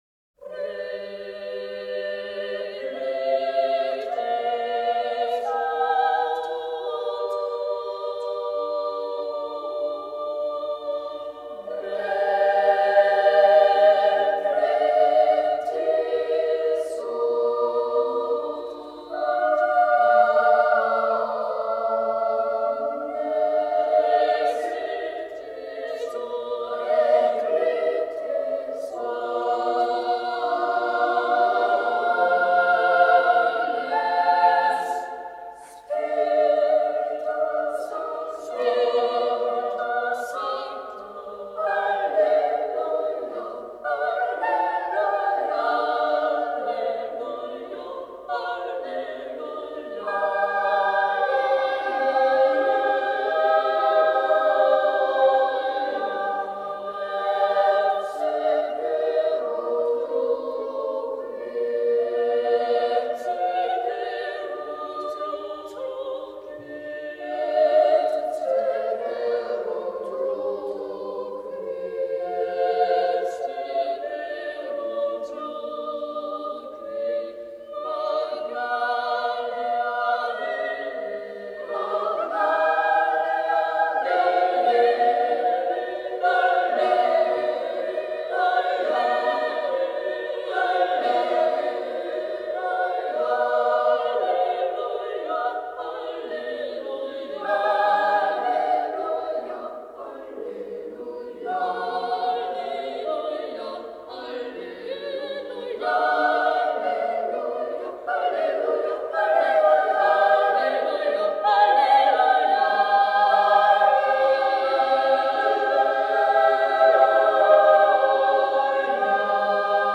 Den musikalischen Anfang macht die achtstimmige Motette "Repleti sunt" von Jacobus Gallus, original für Knabenchor geschrieben.
Today's musical offering is an eight-part motet by Jacobus Gallus, written for boys' voices.
This recording was made in 1972